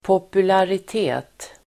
Ladda ner uttalet
Uttal: [popularit'e:t]